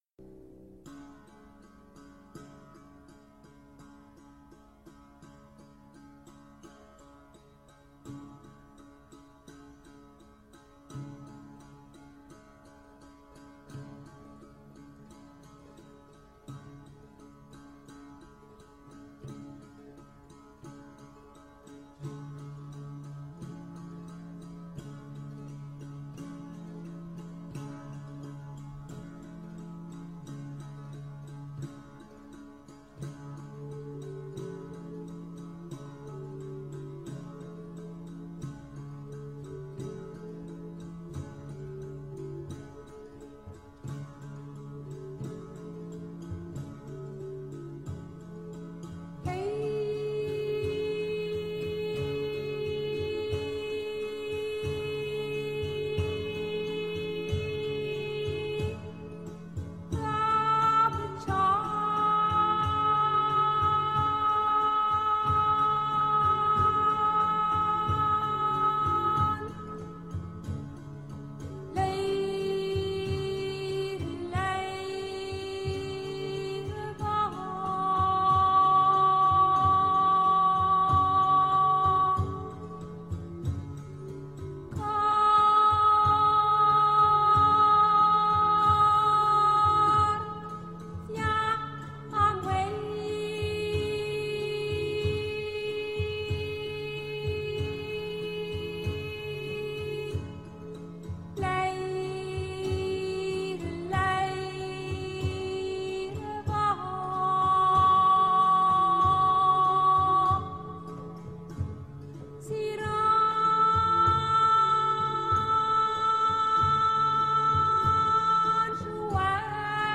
Medieval music and singing: